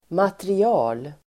Uttal: [materi'a:l]